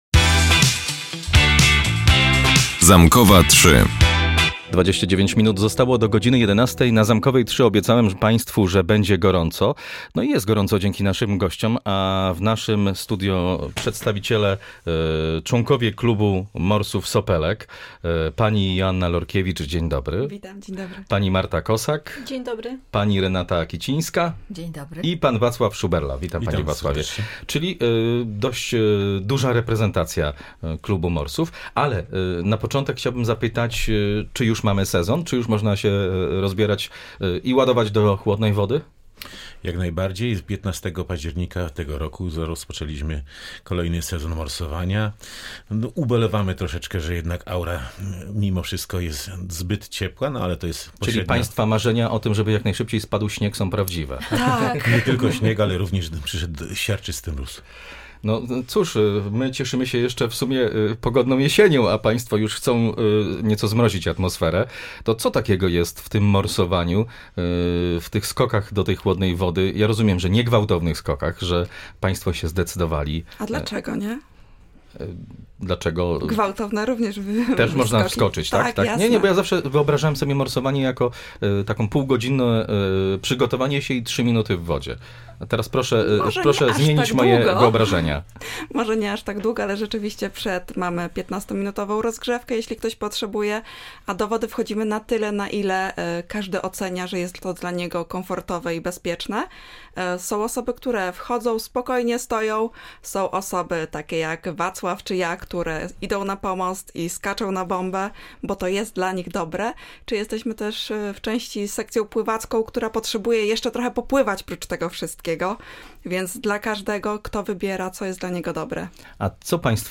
Rozpoczyna się sezon na morsowanie. O pozytywnych aspektach kontaktu z zimną wodą rozmawialiśmy z członkami Rzeszowskiego Klubu Morsów „Sopelek”.